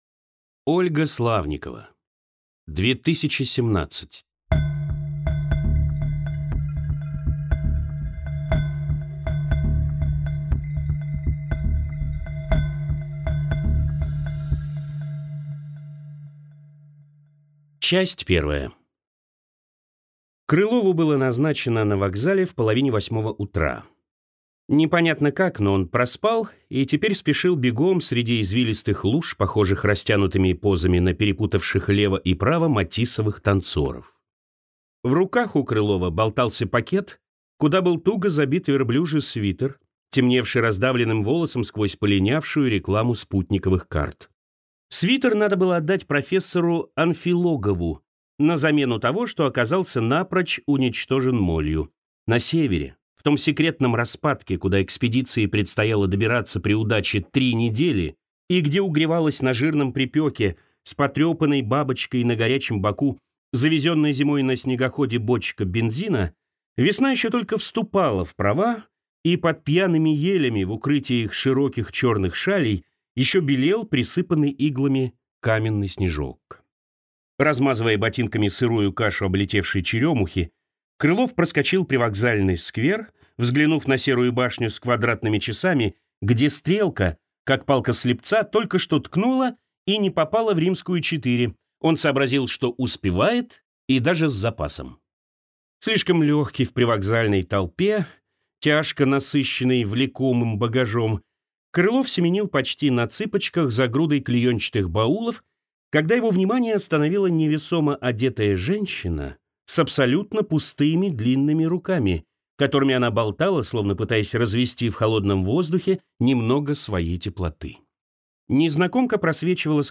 Аудиокнига 2017 | Библиотека аудиокниг
Прослушать и бесплатно скачать фрагмент аудиокниги